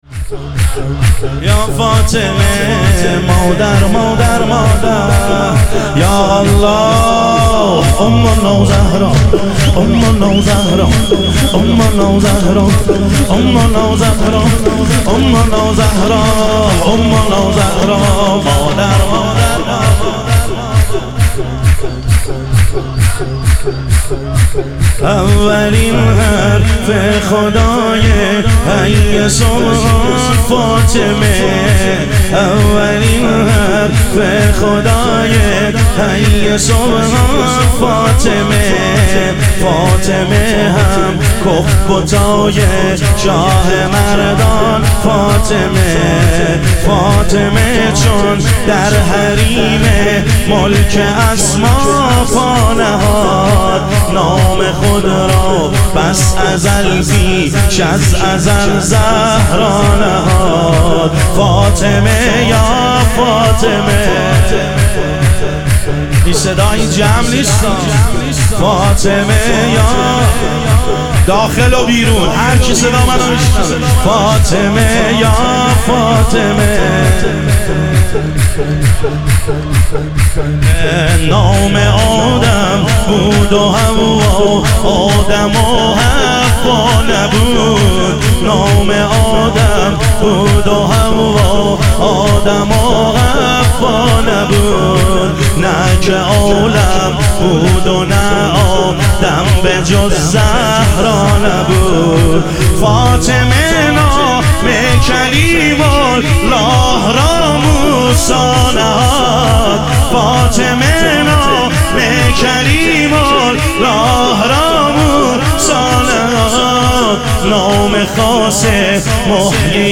ظهور وجود مقدس حضرت زهرا علیها سلام - شور